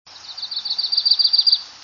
Yellow Rumped Warbler
During migration at Cheesequake State Park, NJ, 4/20/04, (20kb)
warbler_yellow-rumped_737.wav